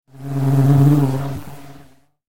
جلوه های صوتی
دانلود صدای حشره 10 از ساعد نیوز با لینک مستقیم و کیفیت بالا
برچسب: دانلود آهنگ های افکت صوتی انسان و موجودات زنده دانلود آلبوم صدای انواع حشرات از افکت صوتی انسان و موجودات زنده